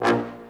BRASSHTA#2.wav